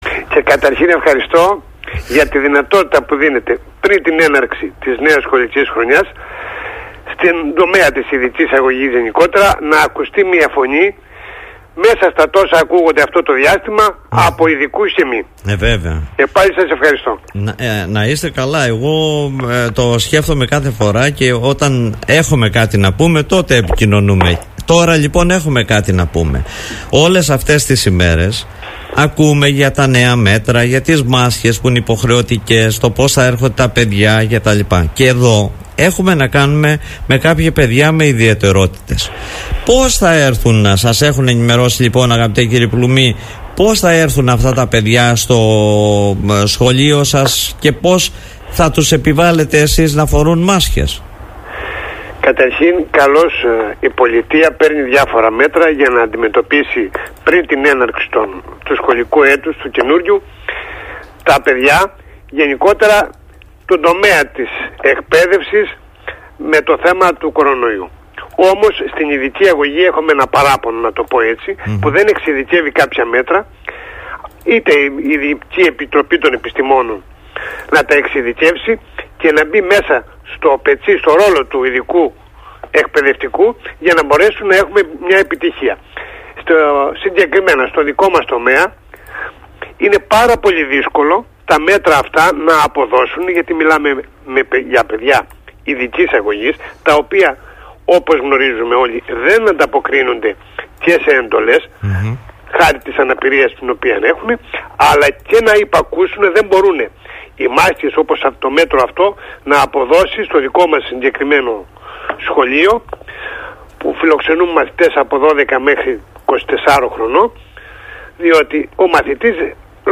μιλώντας στον Politica 89.8